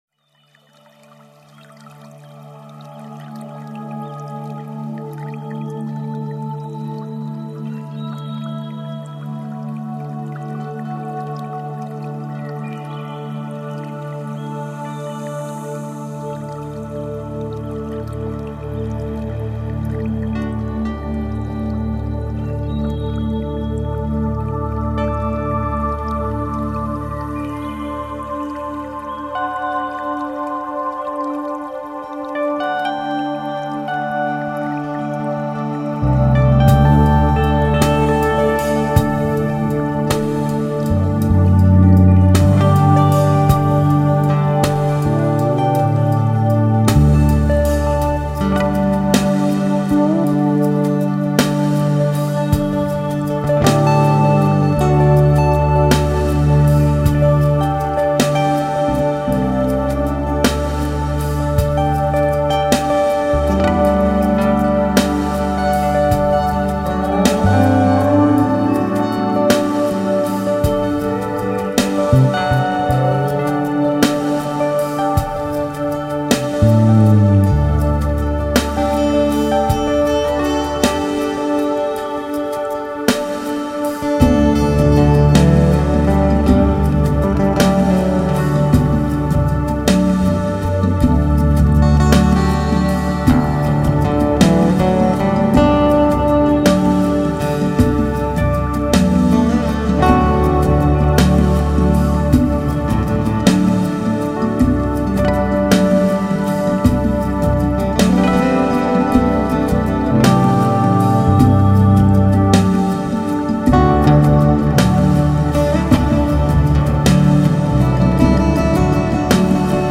New Age